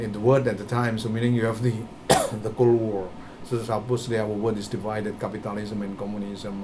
S1 = Taiwanese female S2 = Indonesian male Context: S2 is talking about religion and communism.
The absence of a clear [l] in world may have contributed to the problem.
In addition, S2 is speaking quite fast.